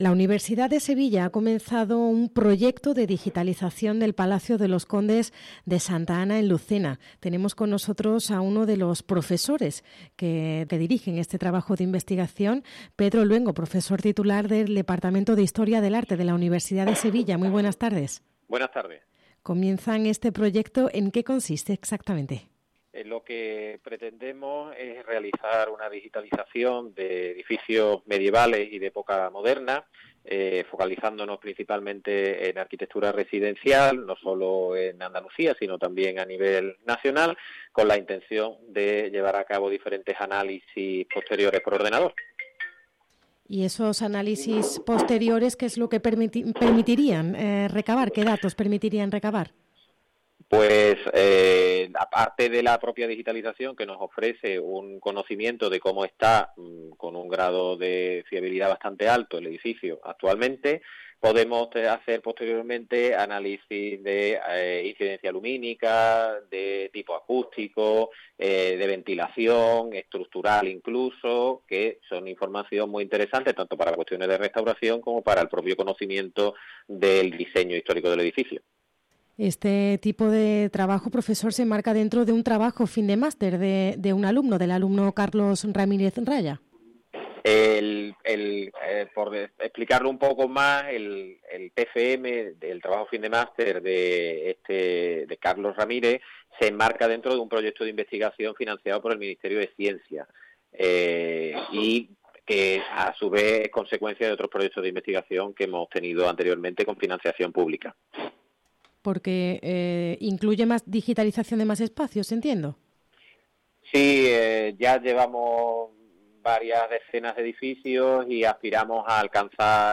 ENTREVISTA | Proyecto Digitalización Palacio Condes Santa Ana Lucena